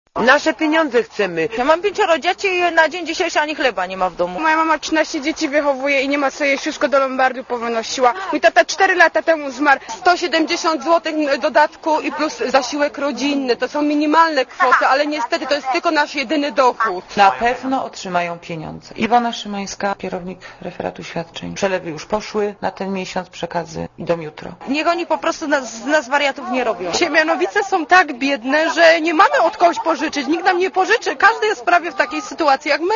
Komentarz audio
pikietasiemianowice.mp3